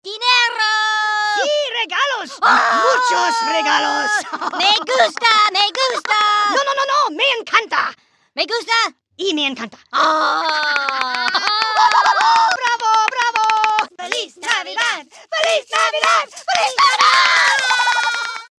Love, Joy, Peace is a hoops&yoyo spanish greeting card with sound made for christmas.
Card sound